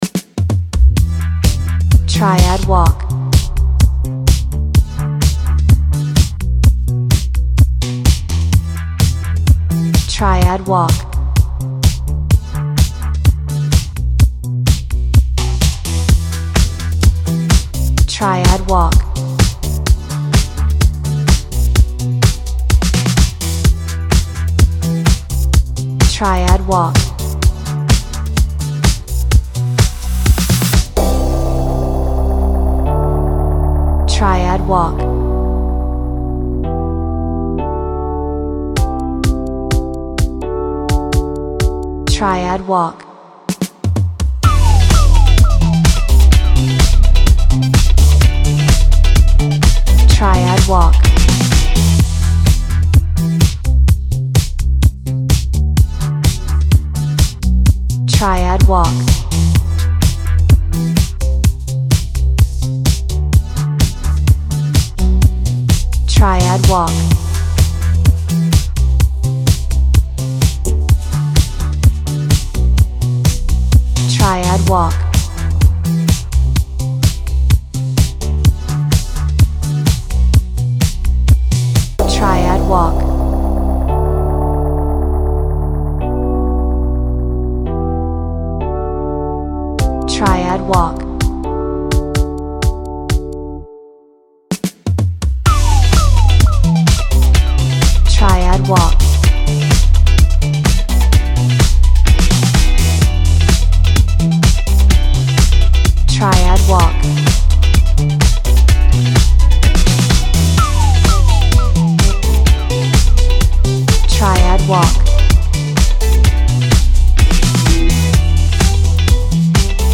エレクトリックピアノ
エレキギター , ベース , ドラム , シンセサイザー